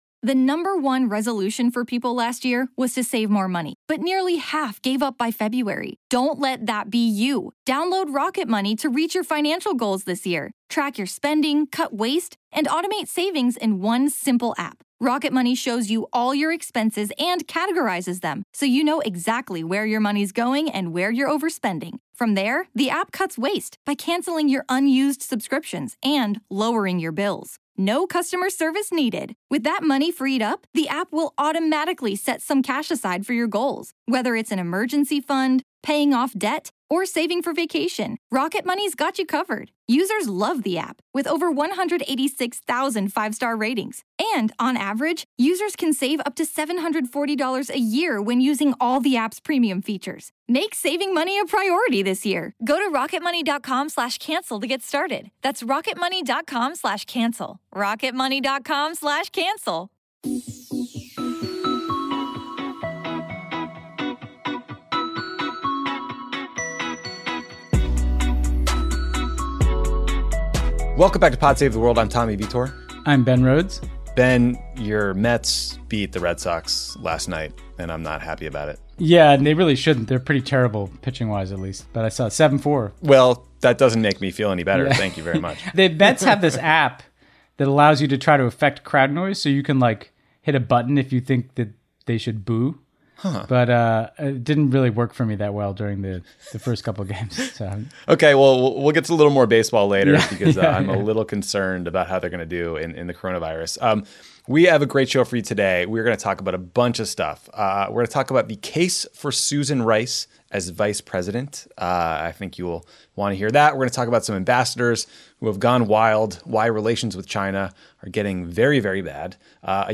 Tommy and Ben make the case for Susan Rice as Vice President, the latest on escalating tensions between the US and China, US plans to sell advanced armed drones abroad, two Trump donors turned ambassadors are engulfed in scandal, anti-corruption protests threaten the government in Bulgaria, Bob Gates writes a book and we’re confused, the truth about TikTok and things aren’t looking good for Major League Baseball. Then Ben interviews Israeli news anchor Yonit Levi about the massive anti-Netanyahu protests in Israel.